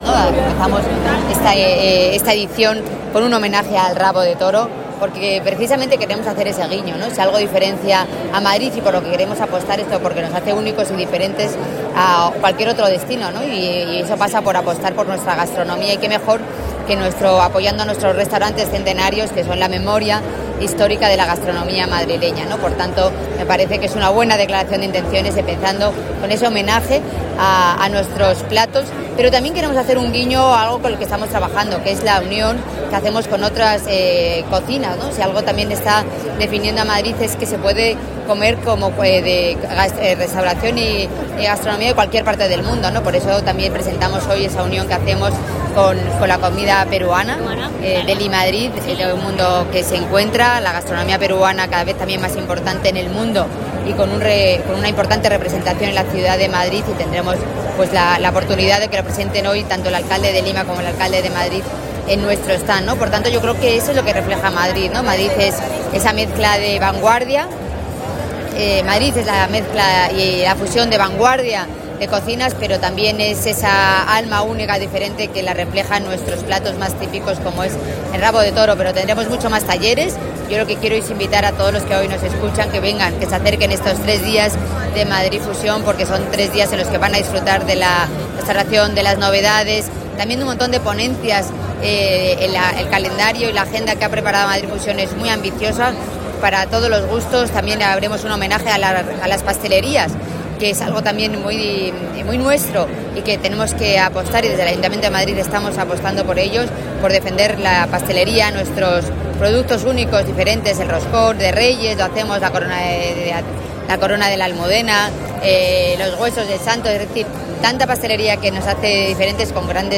AUDIO-Maillo.taller-y-homenaje-al-rabo-de-toro-en-Madrid-Fusion.mp3